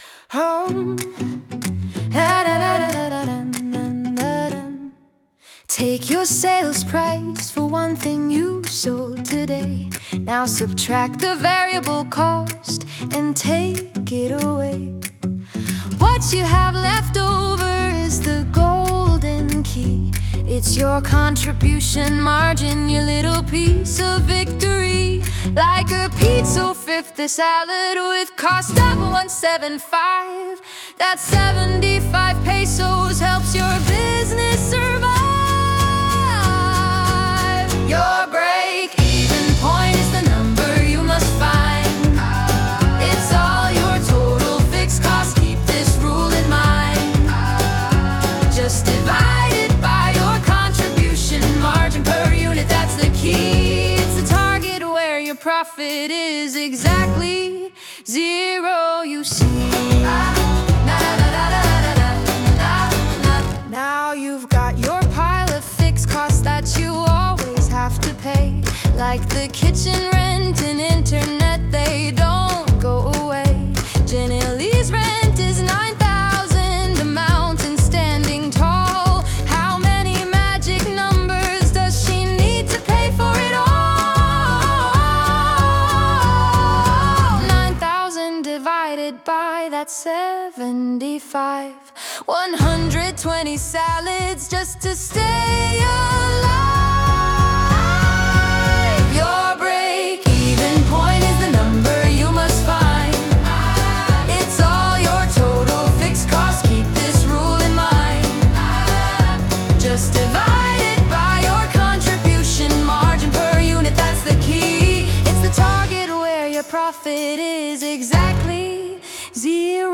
The Sing Along Experience